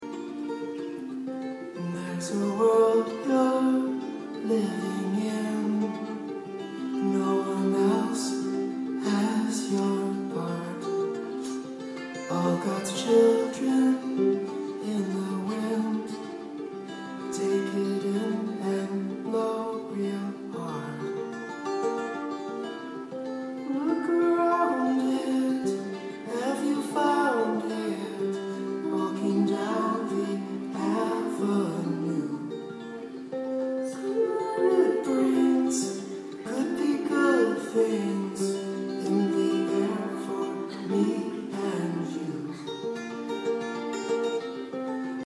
live in 2015